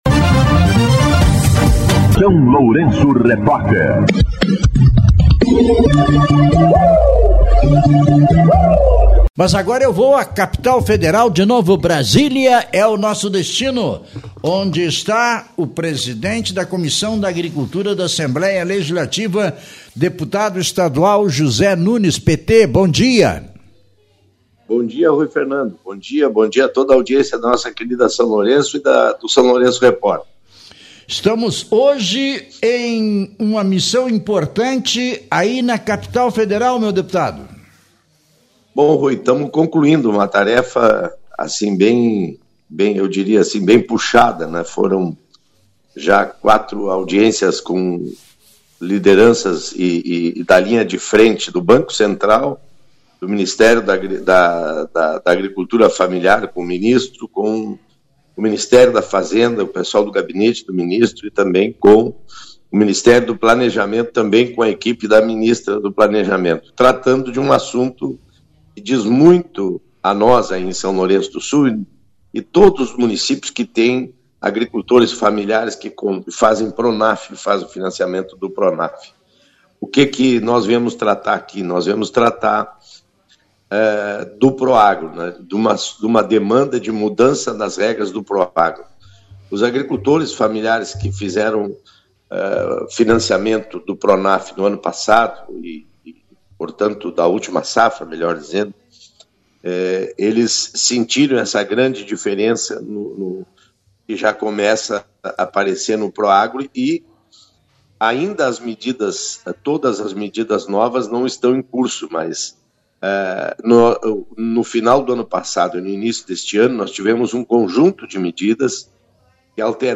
Entrevista com deputado estadual Zé Nunes (PT)